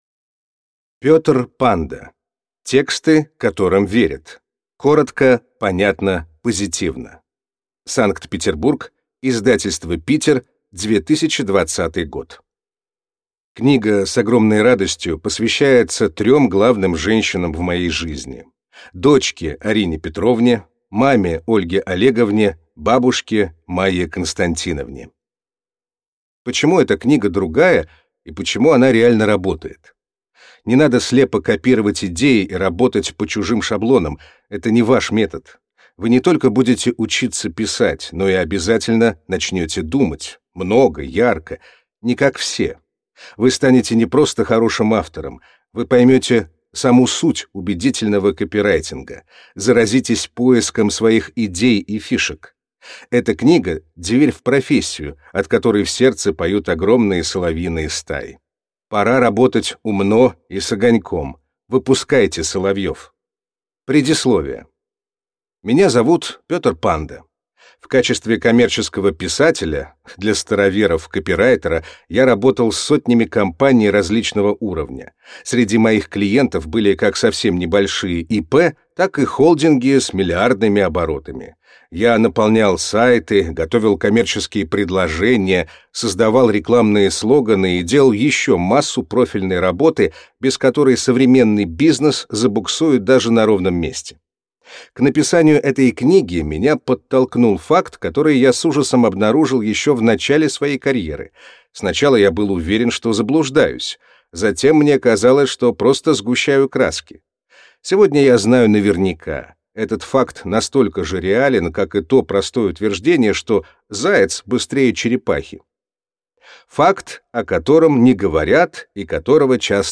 Аудиокнига Тексты, которым верят. Коротко, понятно, позитивно | Библиотека аудиокниг